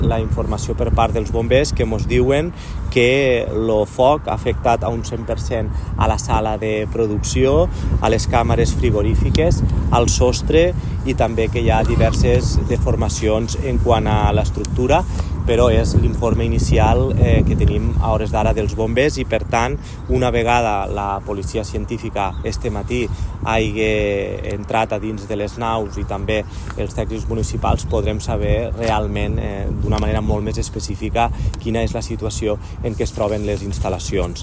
L’alcalde de Tortosa, Jordi Jordan, ha lamentat els fets i ha assegurat que els tècnics municipals accediran a la nau una vegada la Policía Científica finalitze la seua feina per tal d’avaluar l’estat de les instal·lacions i quines mesures prendre.
Tall-Jordan_IncendiEscorxador.mp3